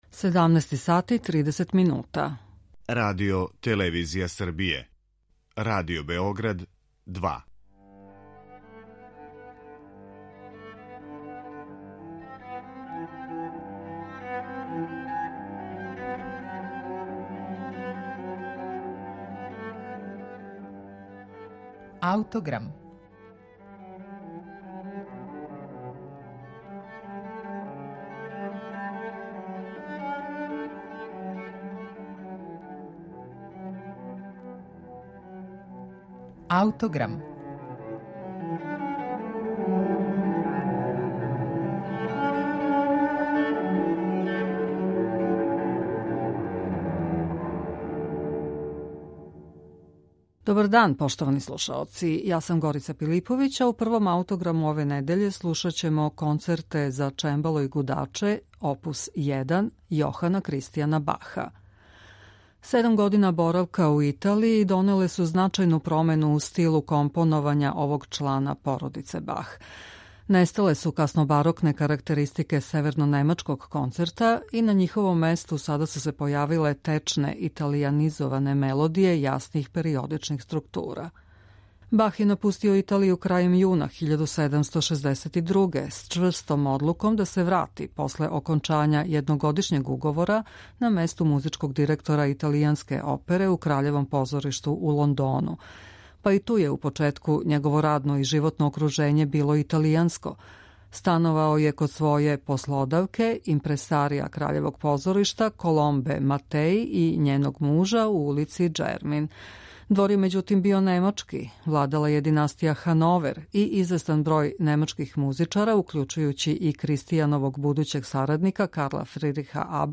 Нестале су каснобарокне карактеристике севернонемачког концерта и на њиховом месту сада су се појавиле течне, италијанизоване мелодије јасних, периодичних структура.